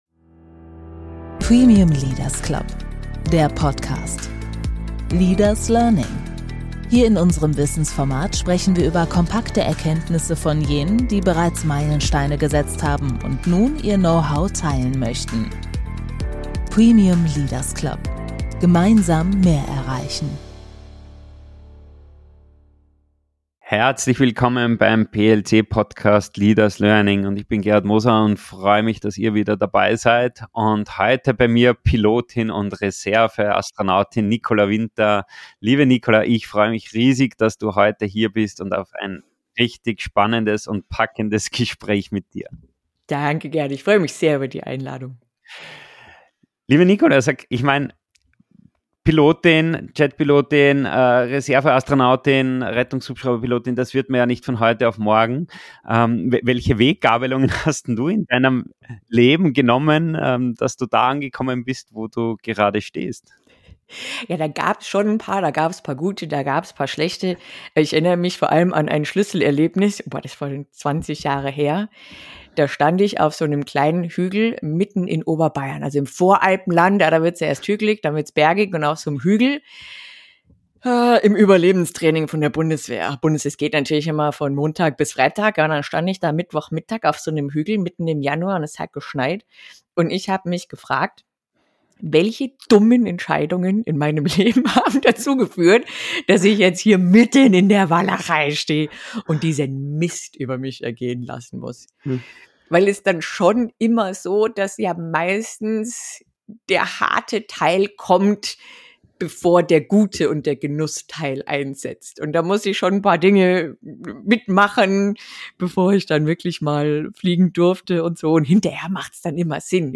Ein Gespräch über Verantwortung, Fokus und Menschlichkeit – und darüber, wie man wirklich besser wird: nicht durch mehr Druck, sondern durch mehr Klarheit, Vorbereitung und bewusste Entscheidungen.